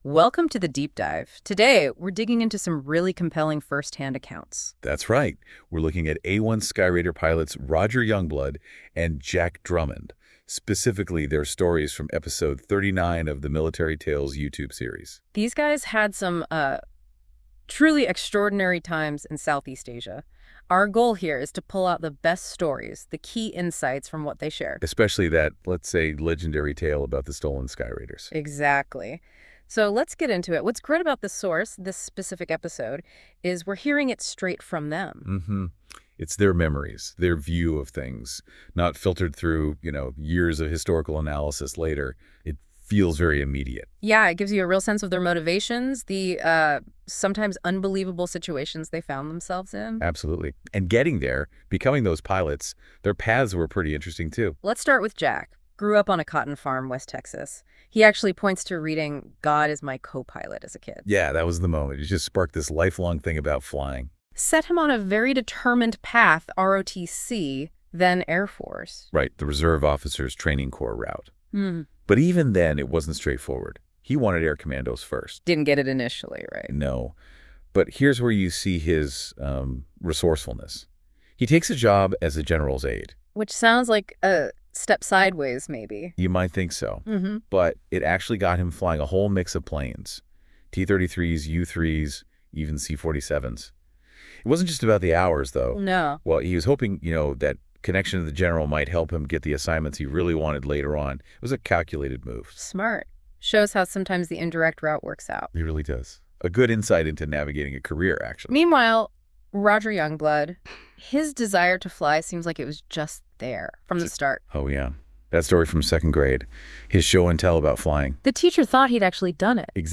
A-1 Skyraider Pilot Interviews